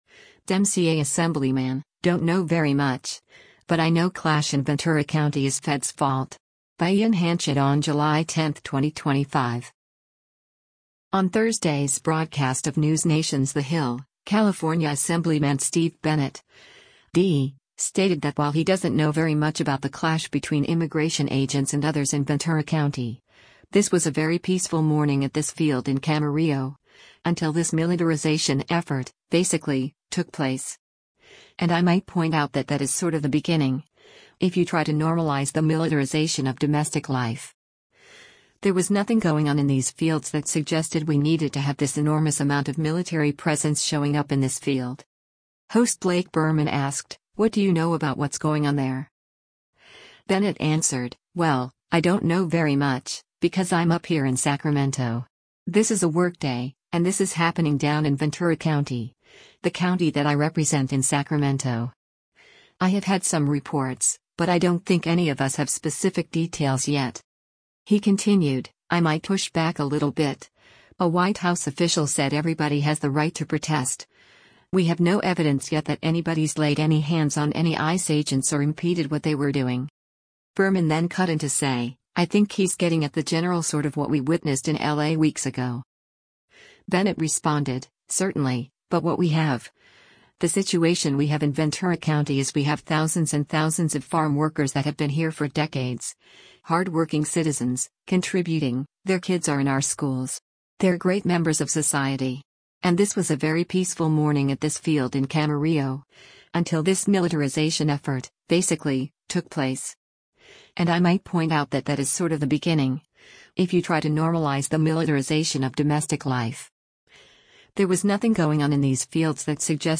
On Thursday’s broadcast of NewsNation’s “The Hill,” California Assemblyman Steve Bennett (D) stated that while he doesn’t know very much about the clash between immigration agents and others in Ventura County, “this was a very peaceful morning at this field in Camarillo, until this militarization effort, basically, took place.